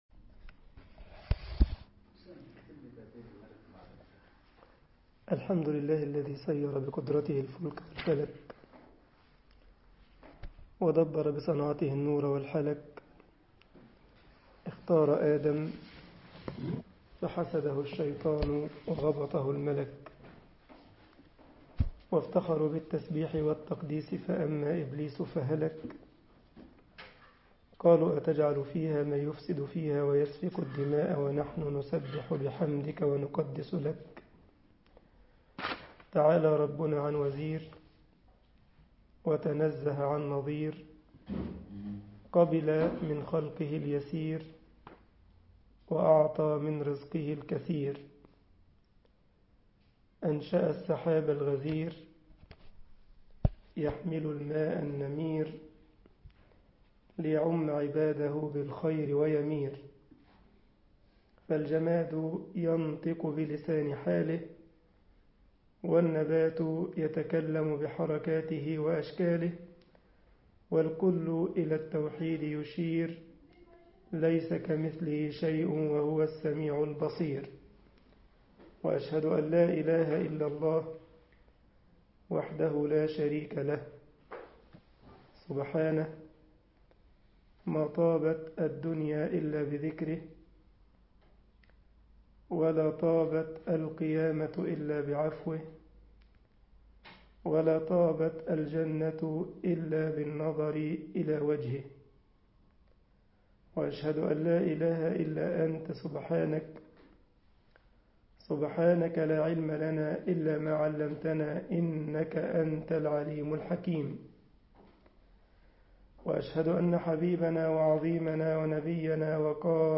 مسجد الجمعية الإسلامية بالسارلند ـ ألمانيا